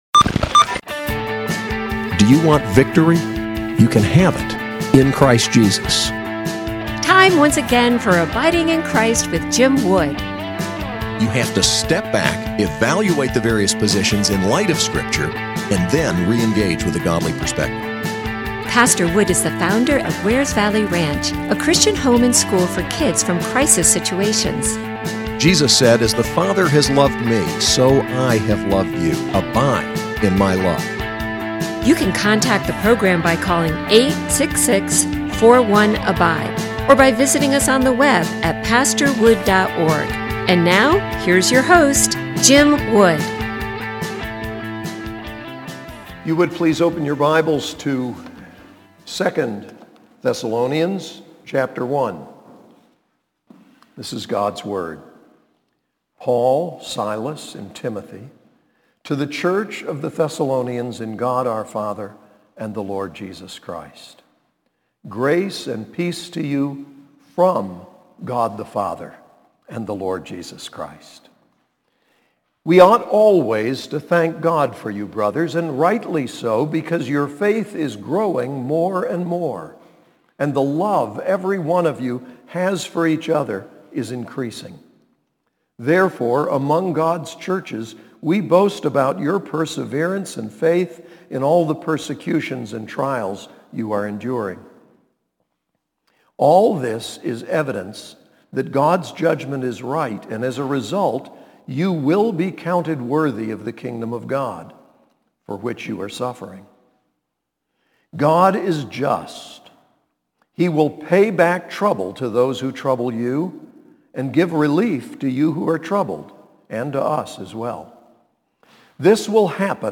SAS Chapel: 2 Thessalonians 1